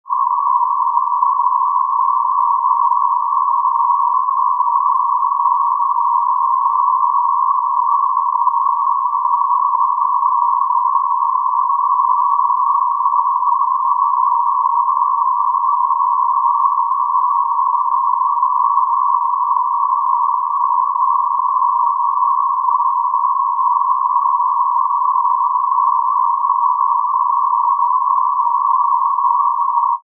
1/3 octave pink noise band at 1 KHz and -12 dB RMS 00' 30" noise023
noise023.mp3